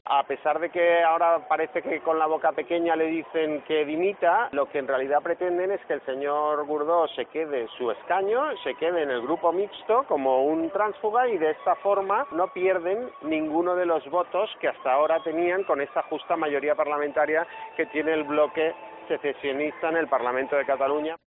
El portavoz de Ciudadanos en el Parlament, Carlos Carrizosa
En declaraciones en una visita a la fiesta mayor de Montcada i Reixac (Barcelona), el dirigente de Ciudadanos se ha referido así a la decisión del TSJC de investigar a Gordó por su posible implicación en caso del 3 % y a la reacción de las formaciones independentistas.